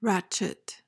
PRONUNCIATION: (RACH-it) MEANING: noun:1.